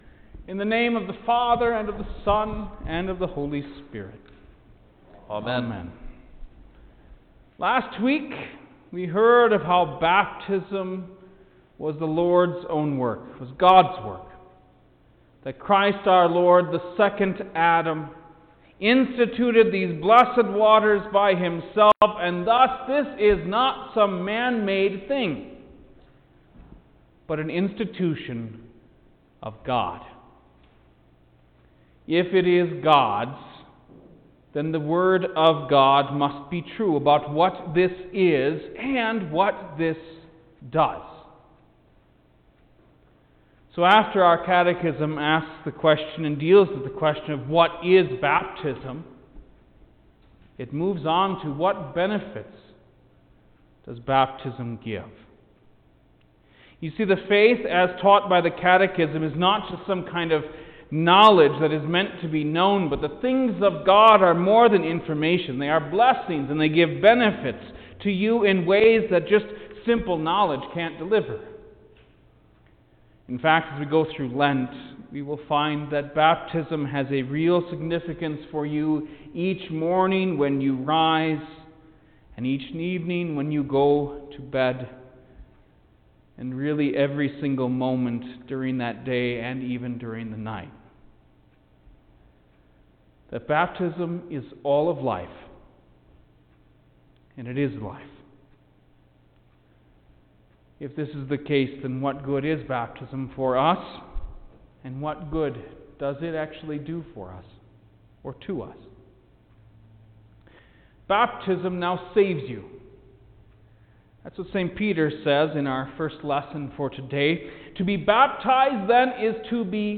March-3_2021_Noon-Lenten-Vespers_Sermon_Stereo.mp3